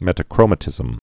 (mĕtə-krōmə-tĭzəm)